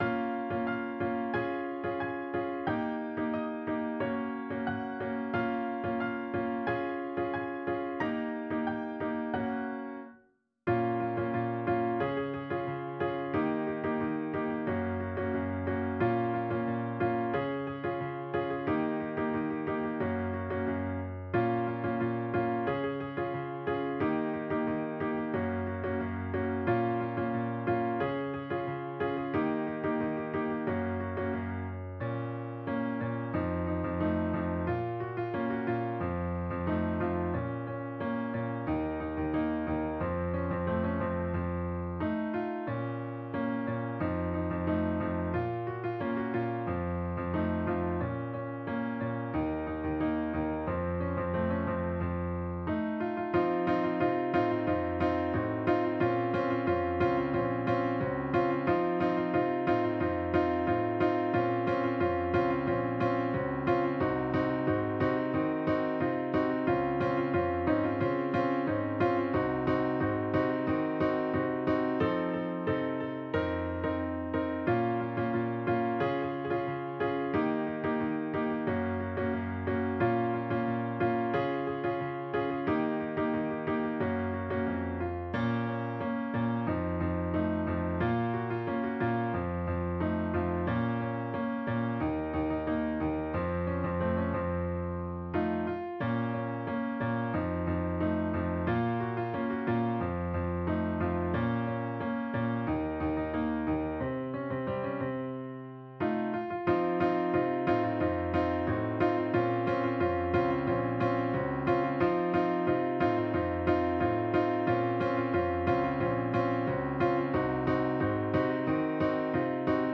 Il y a toujours une introduction au piano avant le chant.